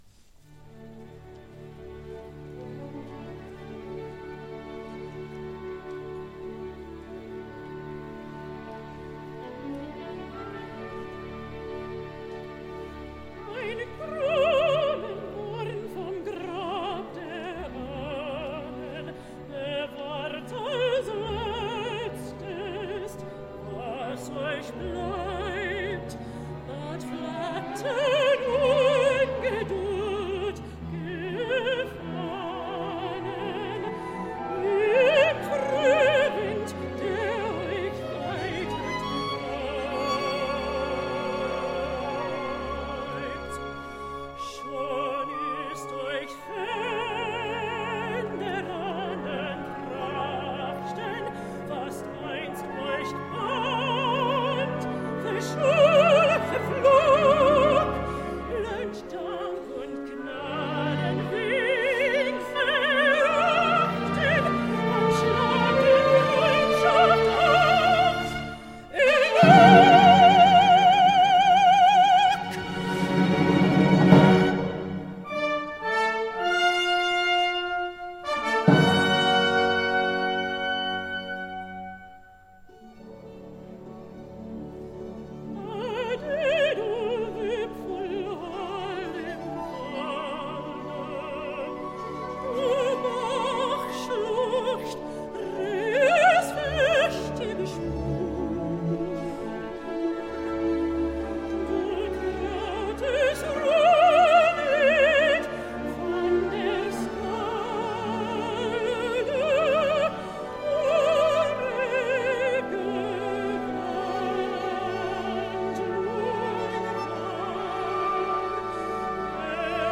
a performance given in Karlsruhe